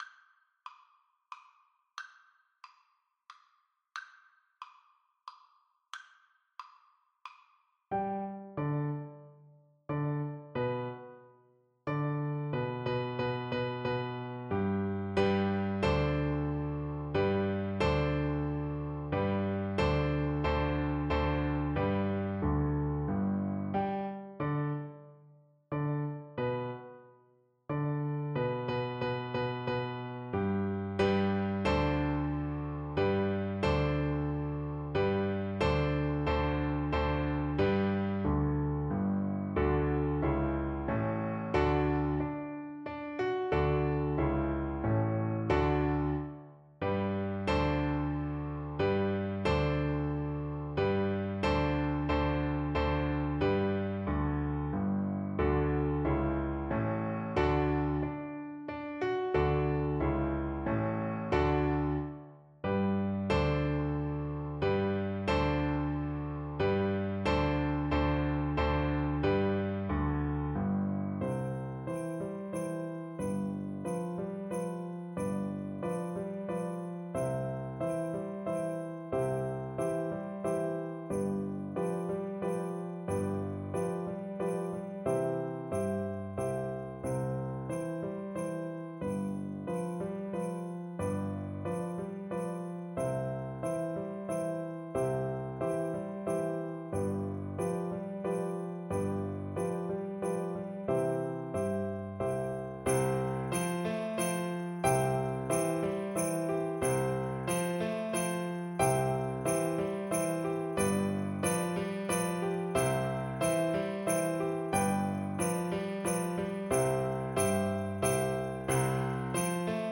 Molto Allegro = c.140 (View more music marked Allegro)
3/4 (View more 3/4 Music)